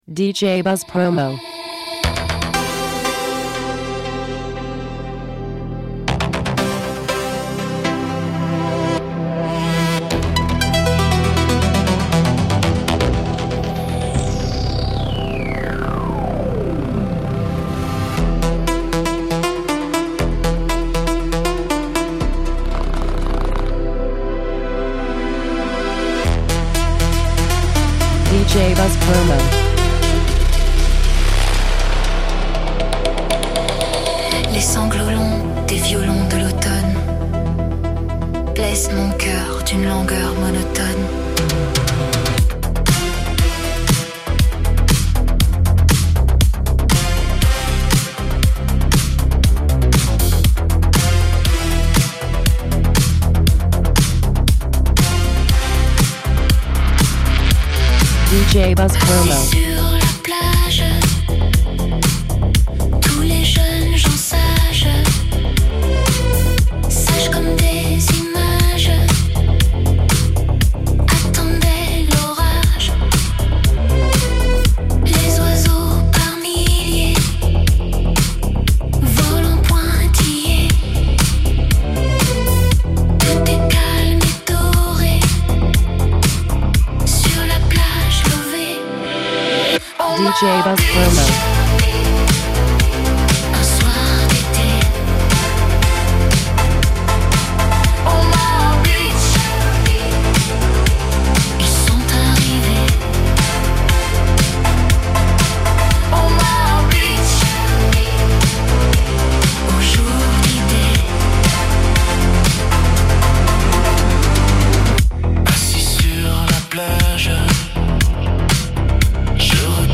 Original Mix
new wave